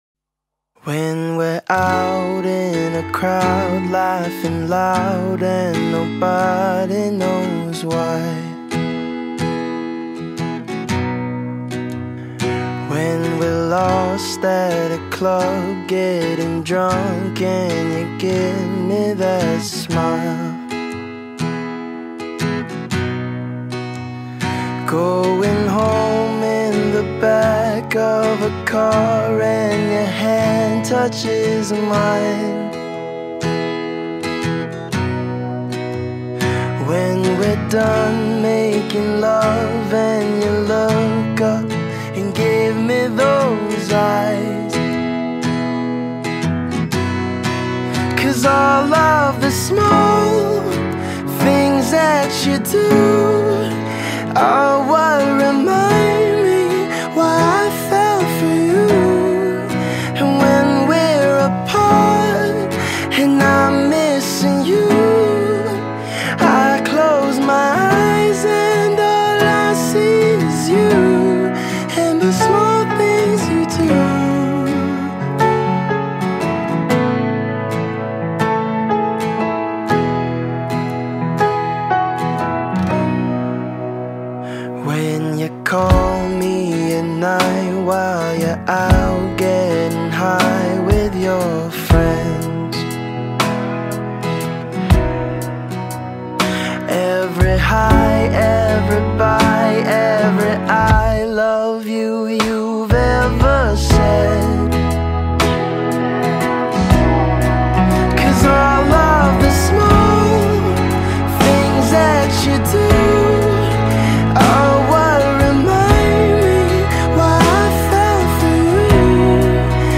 یک آهنگ رومانتیک سادس